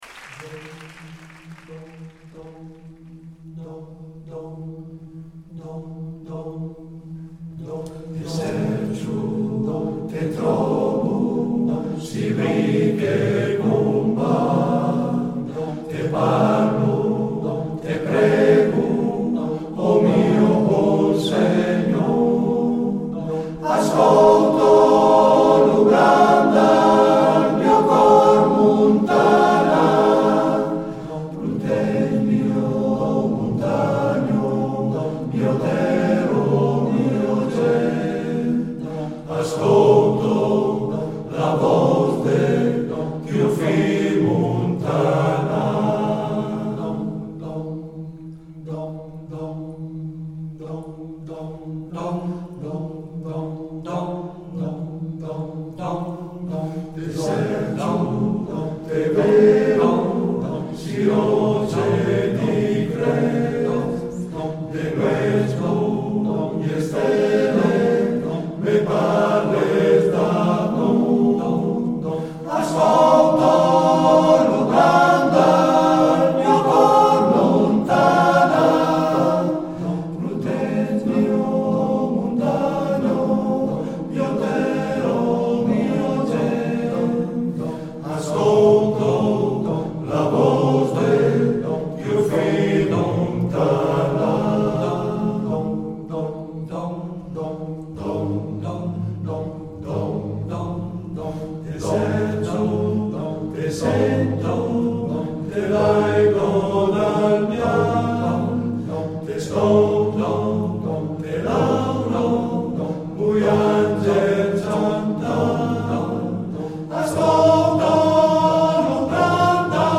Esecutore: Gruppo Corale "La Baita"